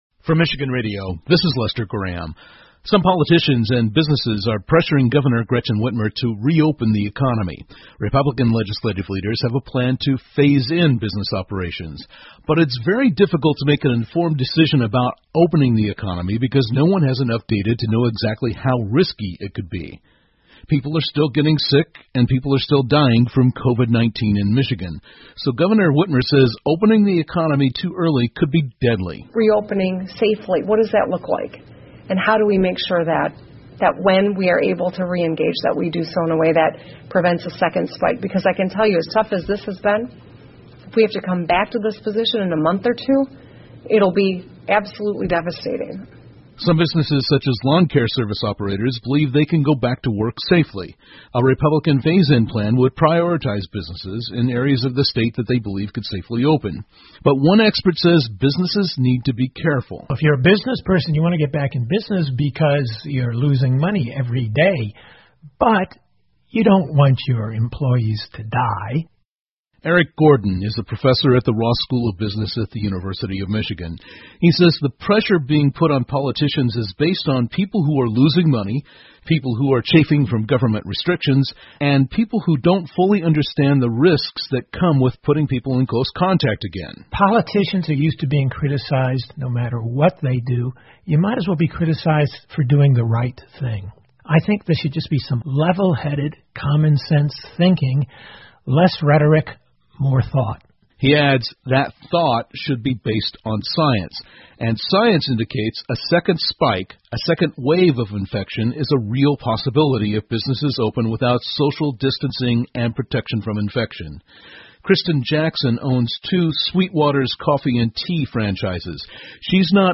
密歇根新闻广播 新冠病毒第二波可能性无法预测 听力文件下载—在线英语听力室